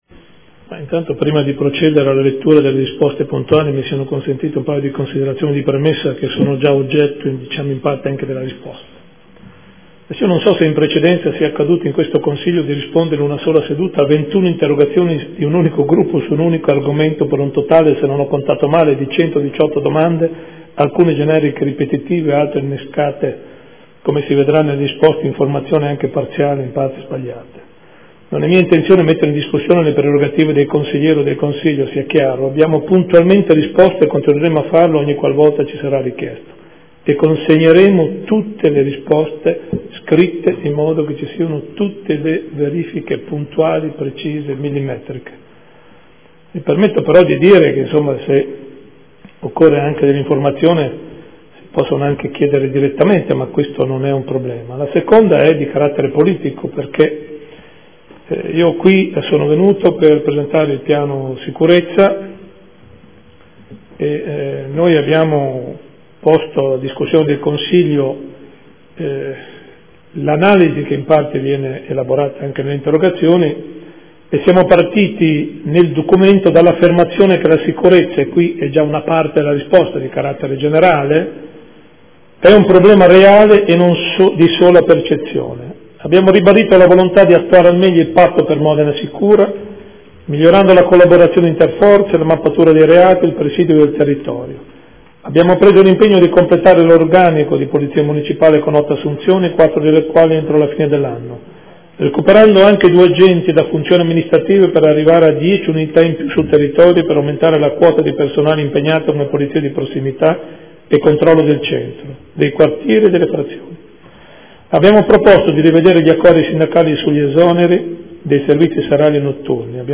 Gian Carlo Muzzarelli — Sito Audio Consiglio Comunale
Seduta del 9/10/2014. Risponde alle seguenti interrogazioni: Prot. Gen. 112722 - Prot. Gen. 112723 - Prot. Gen. 112724 - Prot. Gen. 112725 - Prot. Gen. 112728 - Prot. Gen. 112731 - Prot. Gen. 112737 - Prot. Gen. 112739